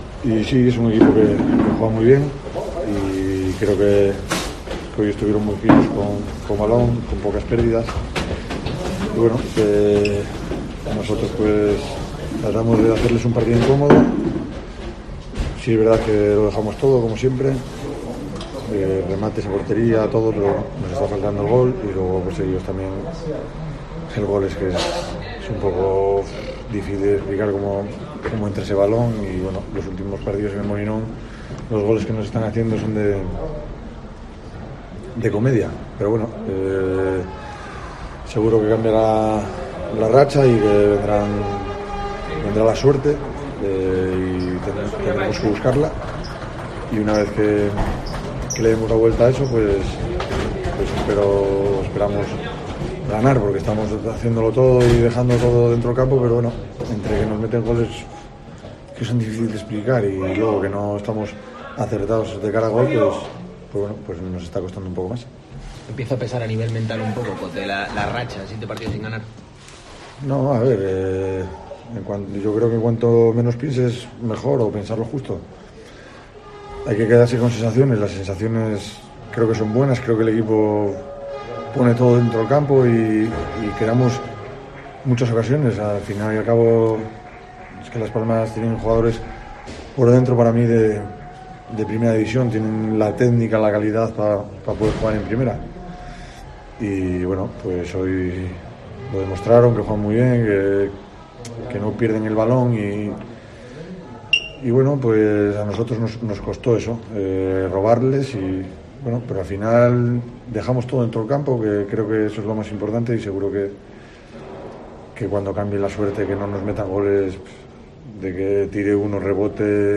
El lateral del Sporting analizó en zona mixta la derrota de su equipo frente a Las Palmas.